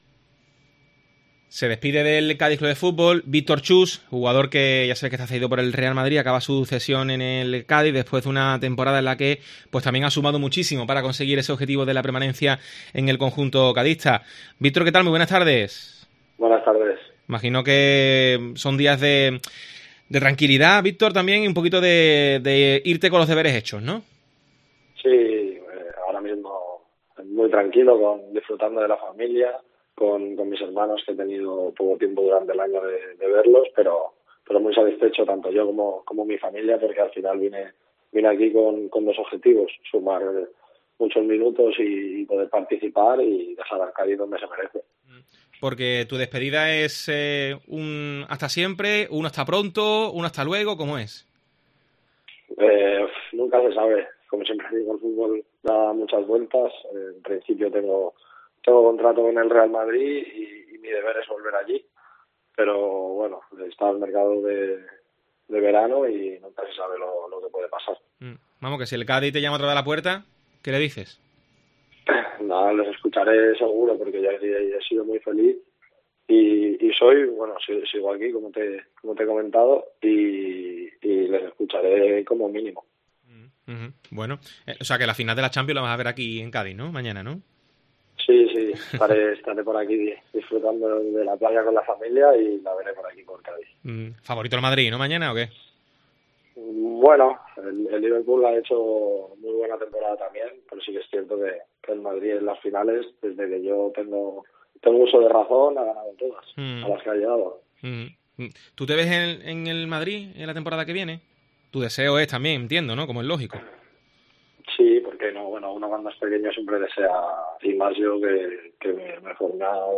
Víctor Chust, en Deportes COPE Cádiz